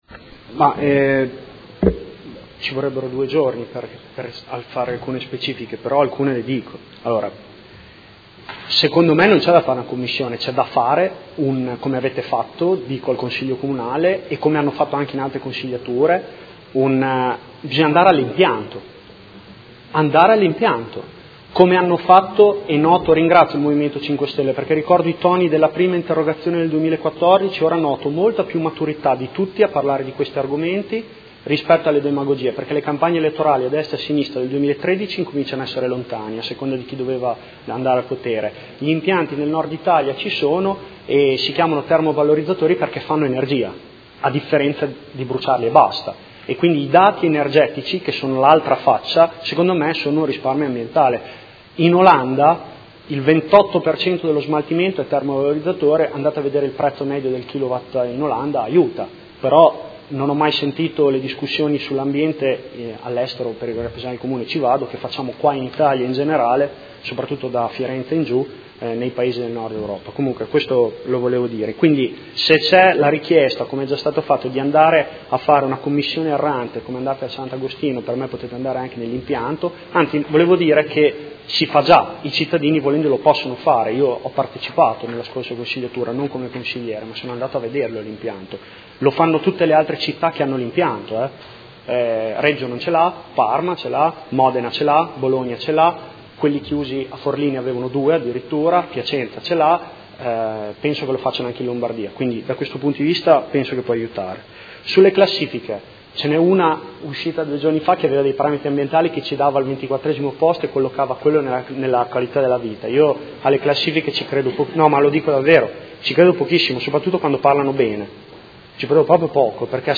Seduta del 1/12/2016 Interrogazione del Gruppo Per Me Modena avente per oggetto: Ulteriori 30.000 tonnellate di rifiuti da smaltire all’inceneritore di Modena e sostenibilità della gestione dei rifiuti.